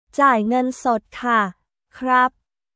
ジャイ Nゲン ソット カ／クラップ